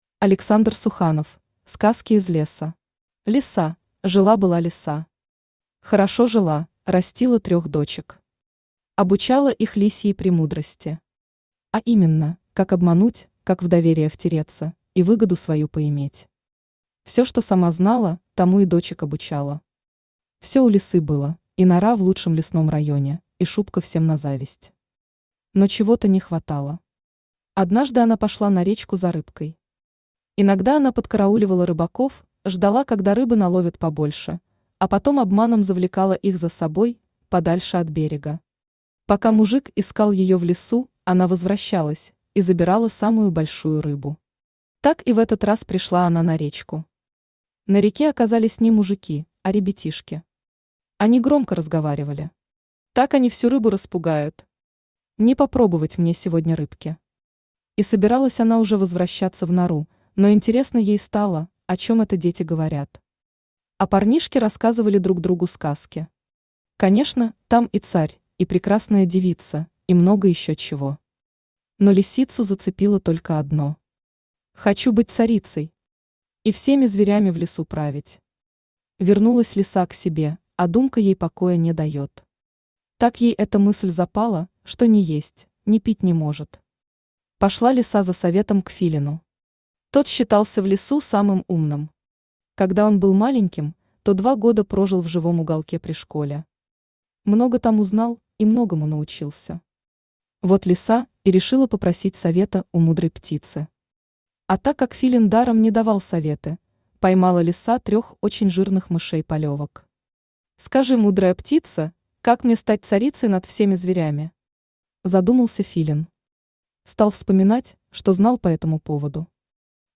Аудиокнига Сказки из леса | Библиотека аудиокниг
Aудиокнига Сказки из леса Автор Александр Суханов Читает аудиокнигу Авточтец ЛитРес.